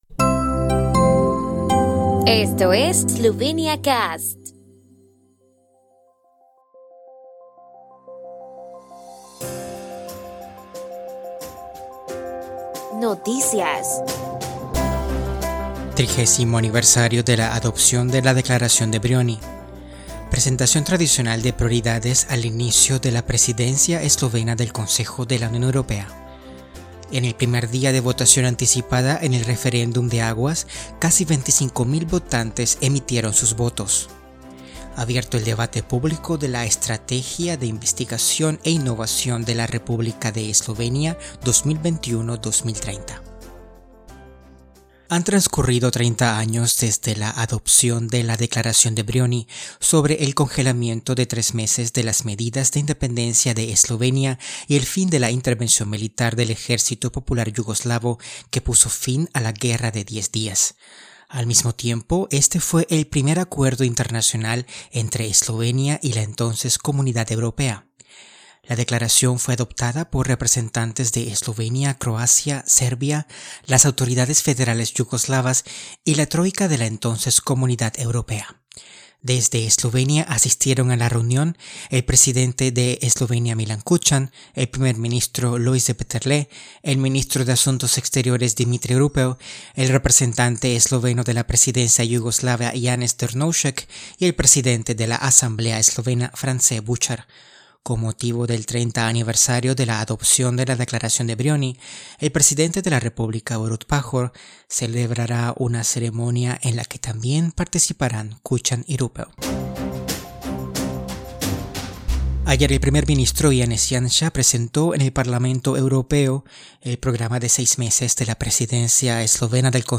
Etiquetado con: Noticias de Eslovenia